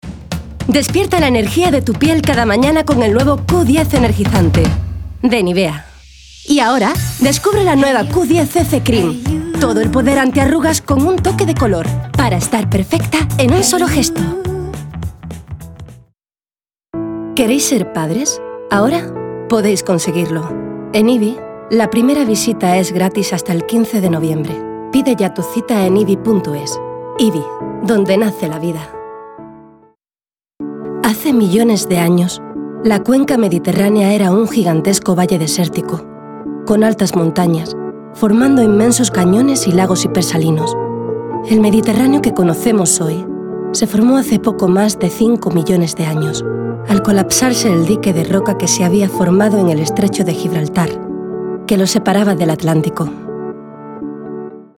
Kein Dialekt
Sprechproben: